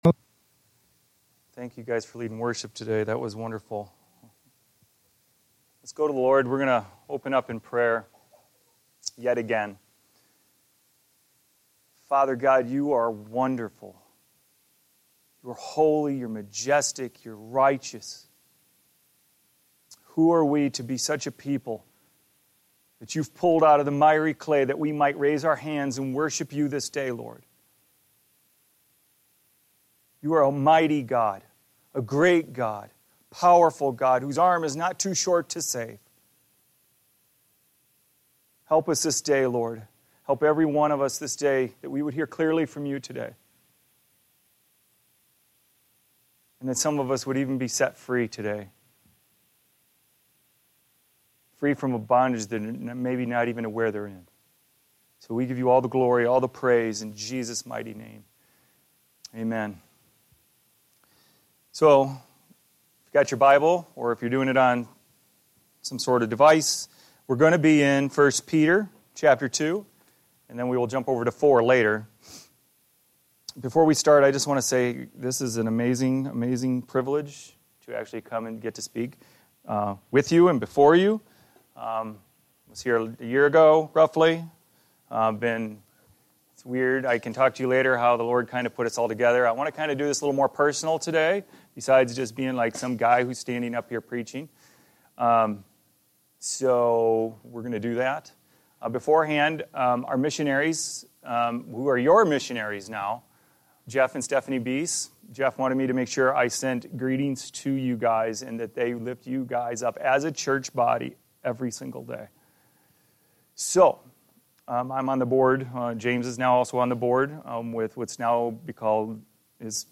New message preached on Sunday, August 26th, 2019 at Revival Church of Fall Creek, WI.